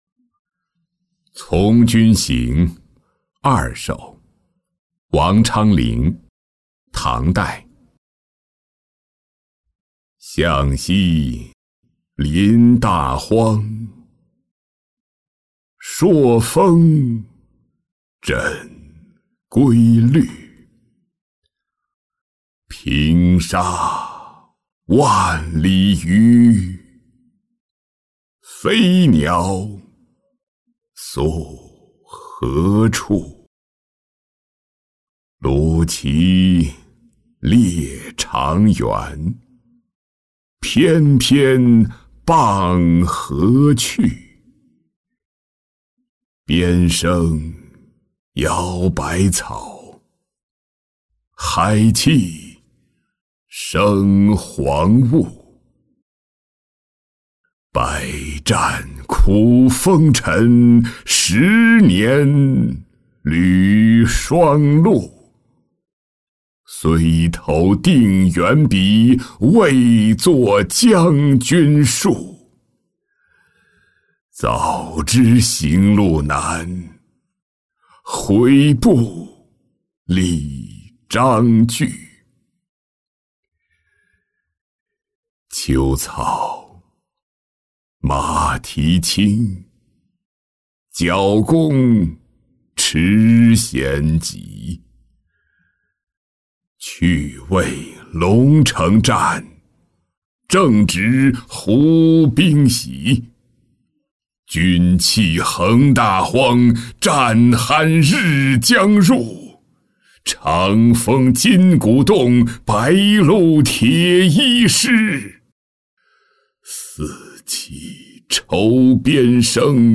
从军行二首-音频朗读